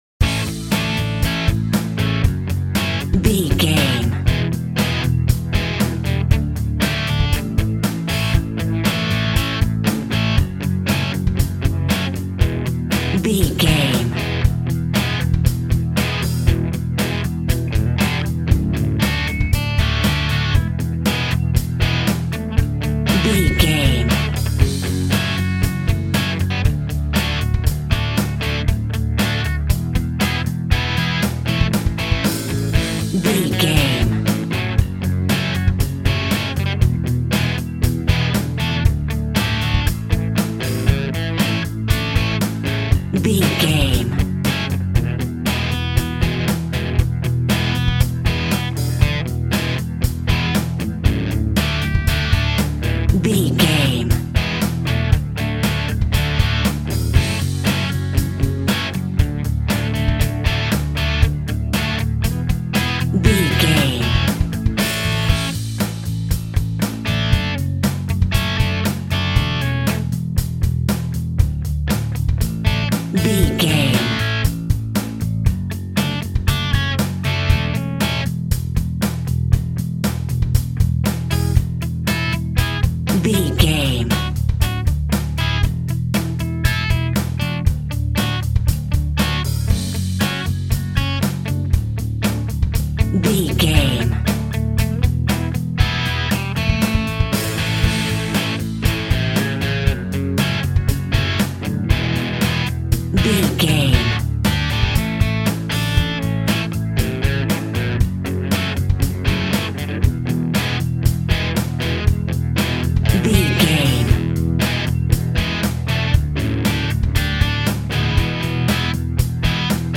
Ionian/Major
Fast
energetic
heavy
aggressive
electric guitar
bass guitar
drums
heavy rock
blues rock
distortion
hard rock
Instrumental rock